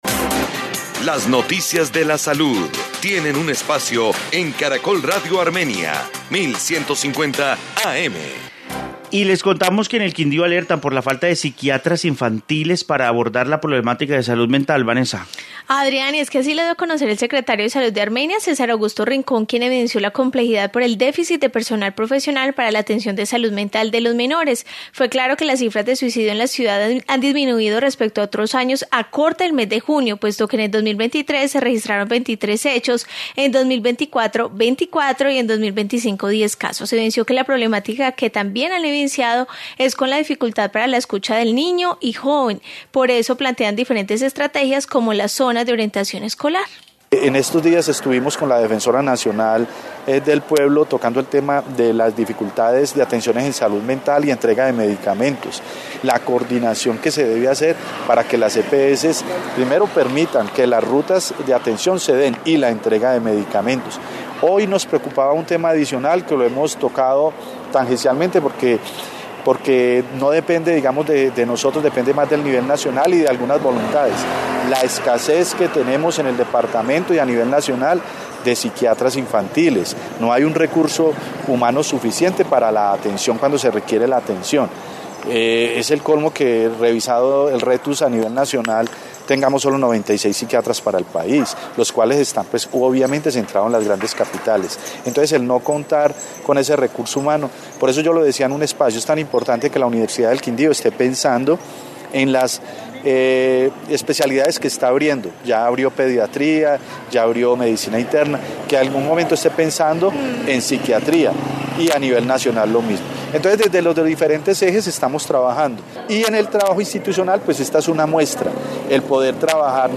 Informe sobre falta de psiquiatras infantiles